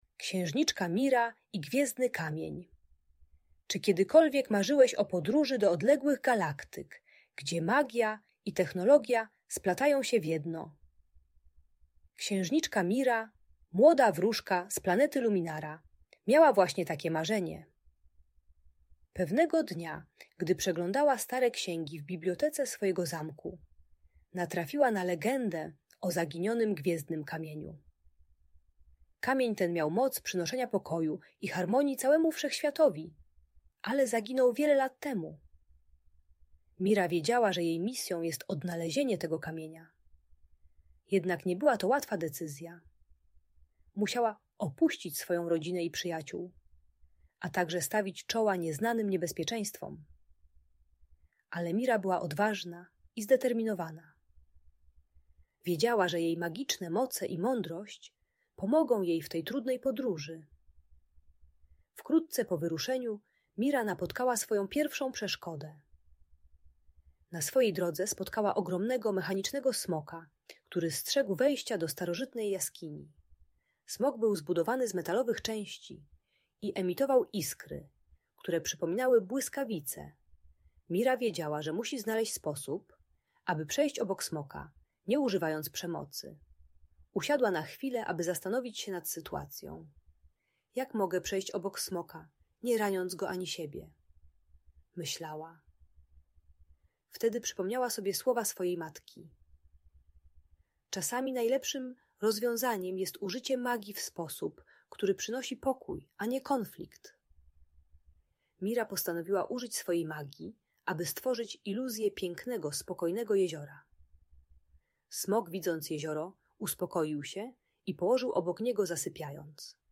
Księżniczka Mira i Gwiezdny Kamień - magiczna histpria - Audiobajka dla dzieci